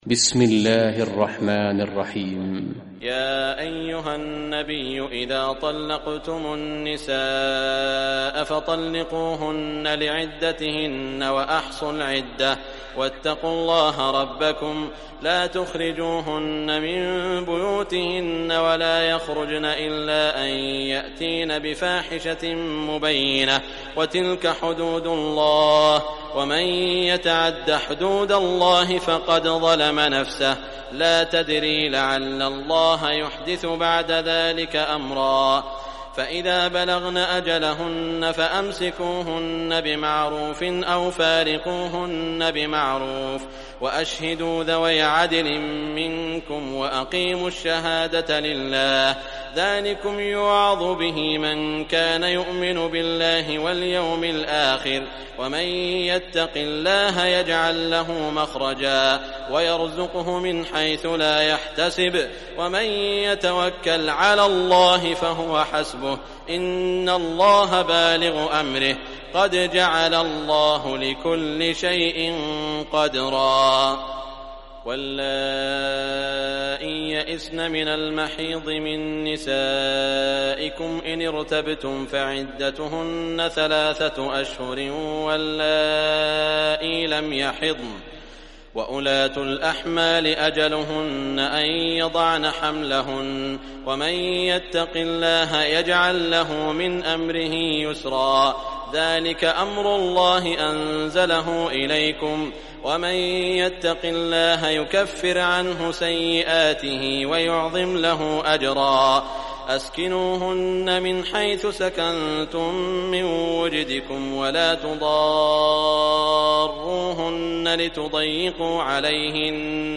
Surah Talaq Recitation by Sheikh Shuraim
Surah Talaq, lsiten or play online mp3 tilawat / recitation in arabic in the beautiful voice of Sheikh Saud al Shuraim.